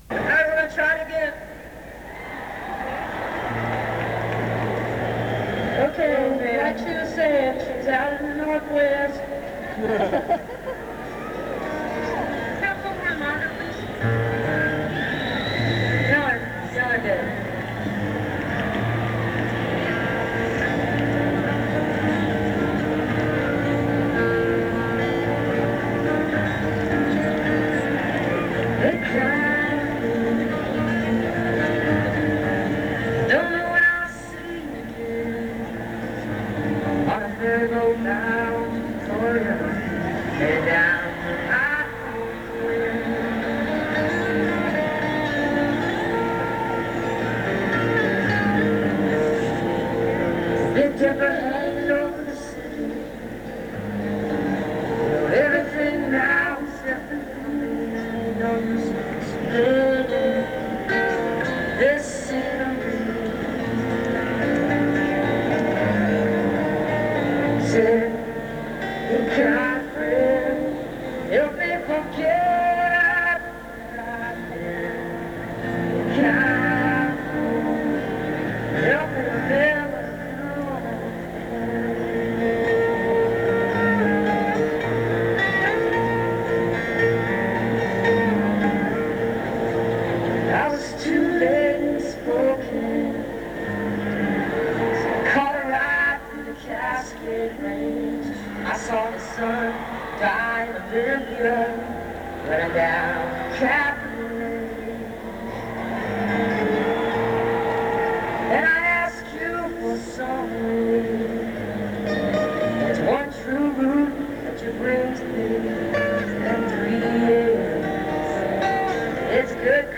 bumbershoot - seattle, washington